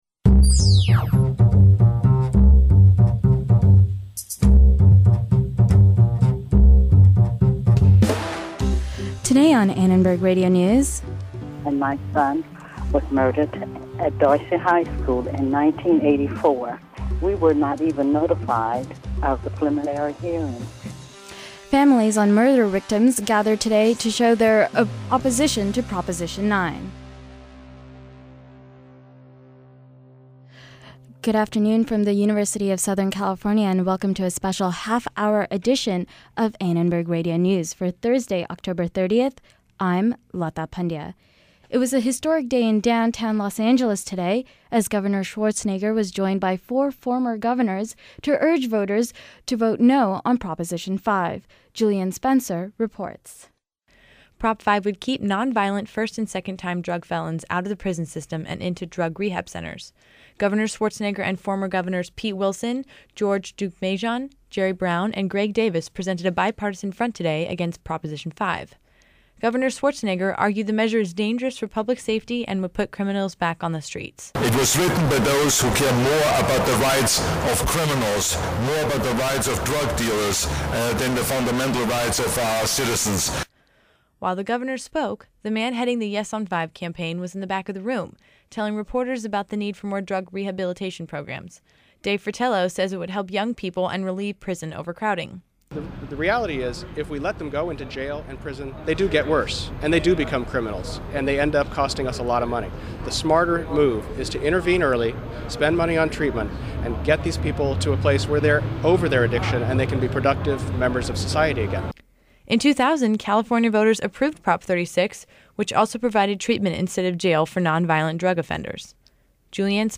In a special half-hour broadcast, we continue our in-depth coverage of the L.A. County Supervisors race.
We talked to people from Thailand to Denmark about what they think will come from a new American President.